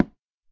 wood2.ogg